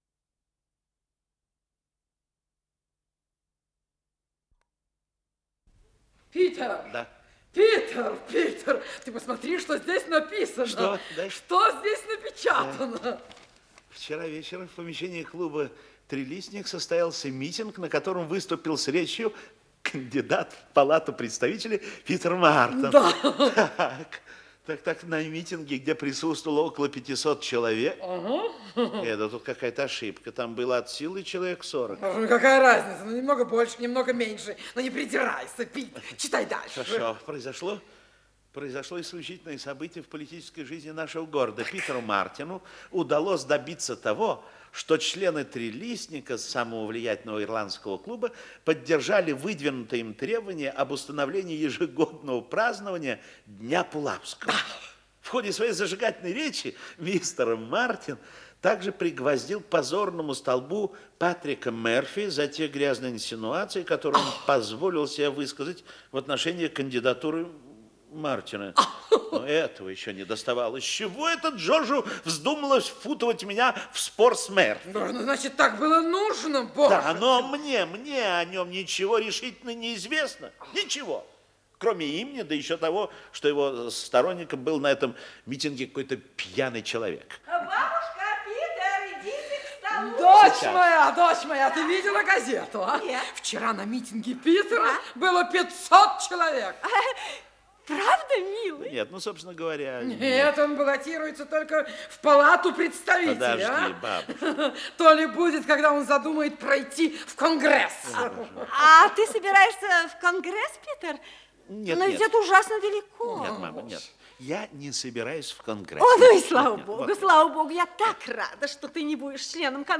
Исполнитель: Артисты московских театров
Радиопостановка